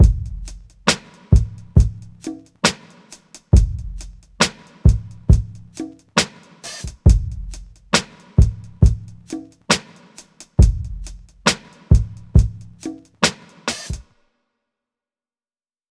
Cardiak_Drum_Loop_2_136bpm.wav